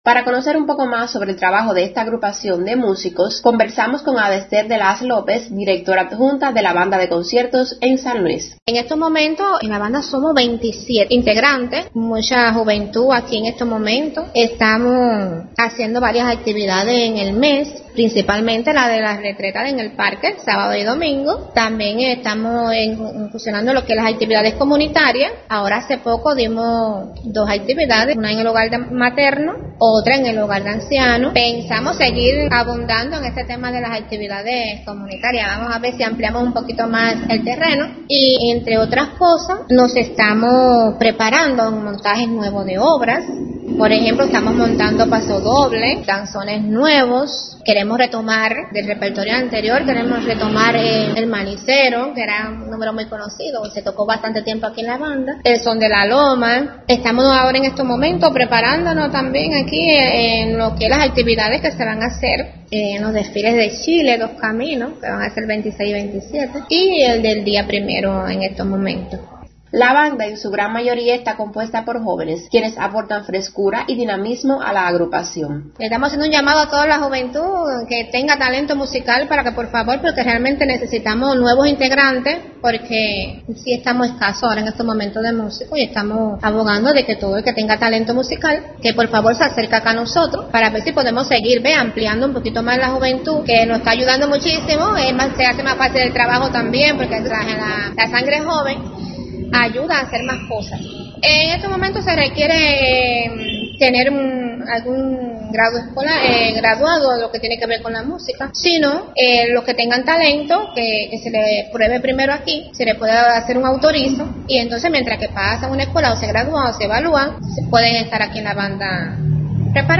_ Para los habitantes de esta localidad es usual que los sábados el parque José Martí, ubicado en el mismo centro del territorio, sea el escenario apropiado para disfrutar de las presentaciones de la Banda Municipal de Conciertos.